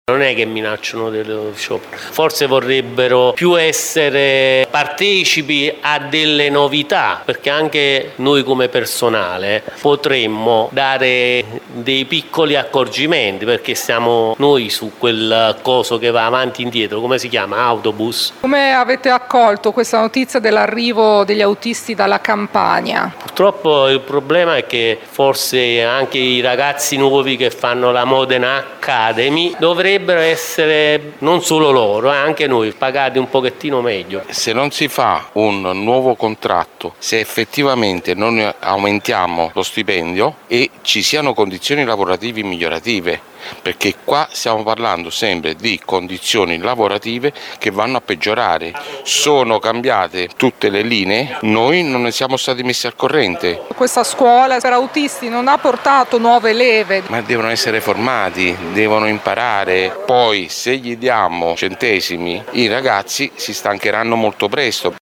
VOX-AUTISTI-SETA.mp3